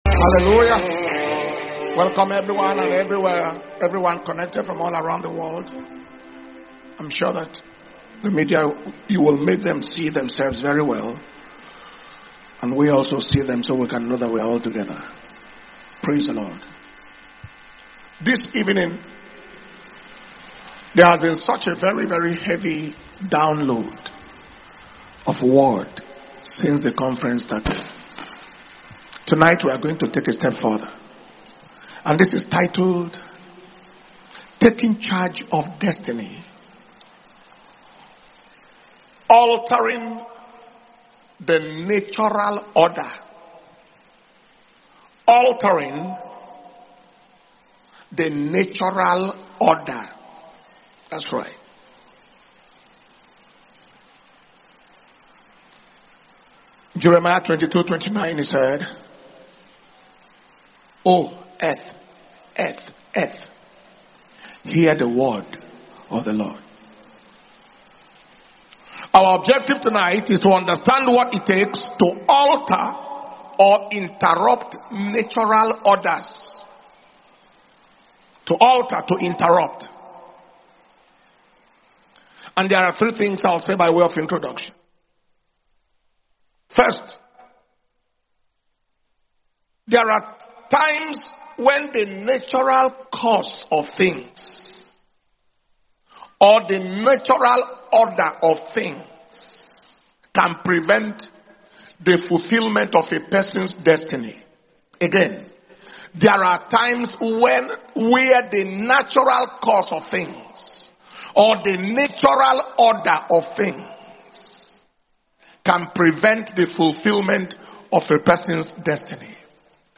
Dunamis Destiny Recovery Convention May 2025